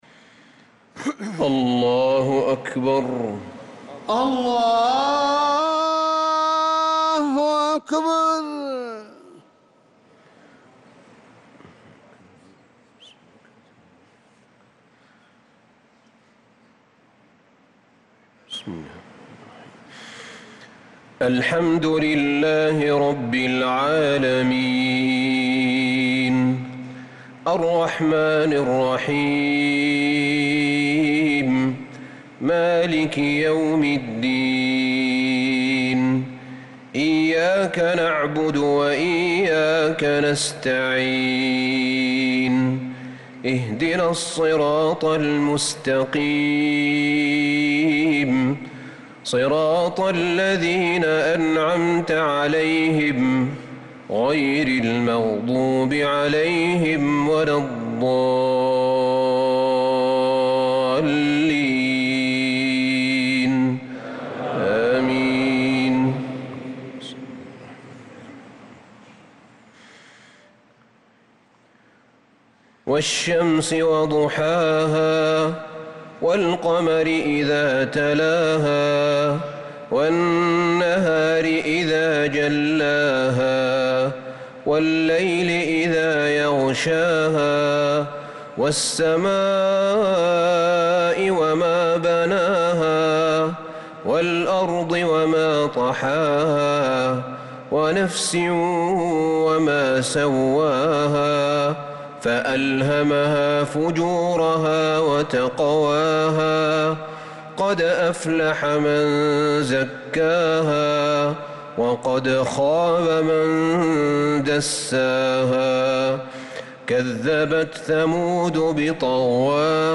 صلاة العشاء للقارئ أحمد بن طالب حميد 25 ربيع الأول 1446 هـ